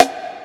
Sn (Psycho).wav